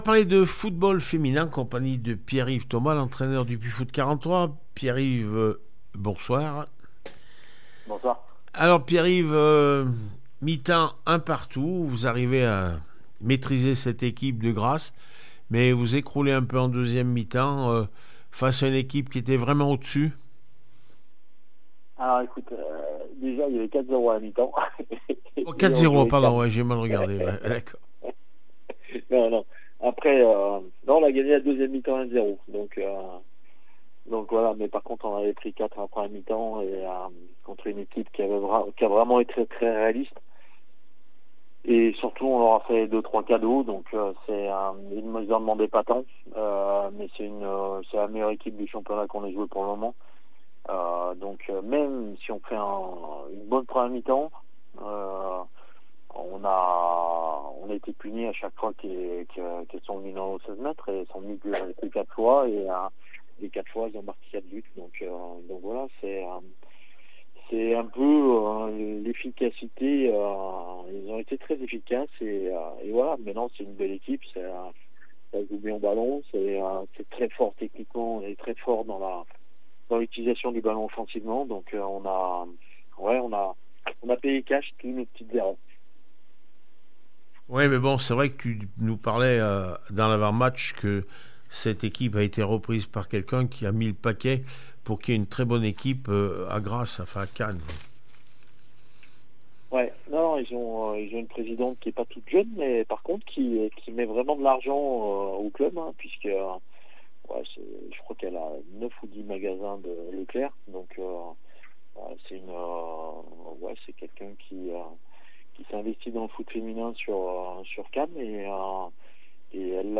27 novembre 2023   1 - Sport, 1 - Vos interviews
d3feminine le Puy foot 43 4-1Cannes réaction après match